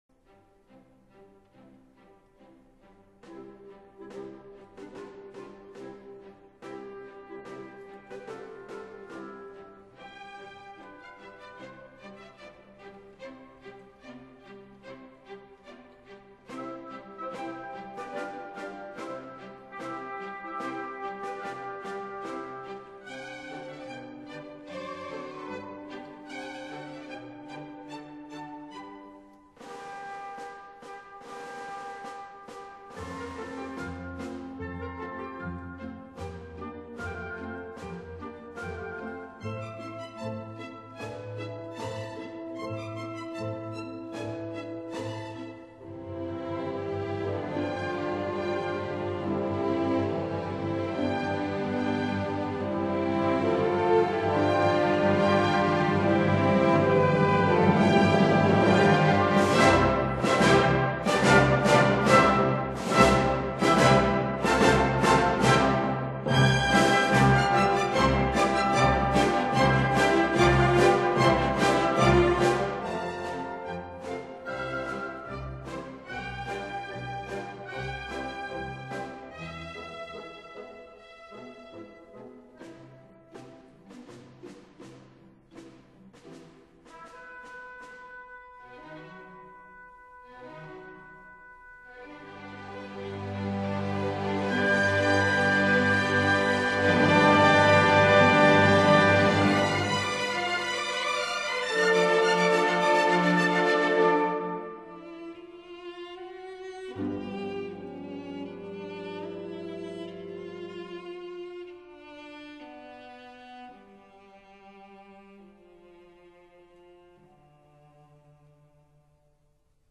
此曲實際上是由4個單獨的圓舞曲加前奏及尾聲所組成。
一個又一個優美的圓舞曲，輕鬆的樂句伴以生動的木管樂器複旋律。
for orchestra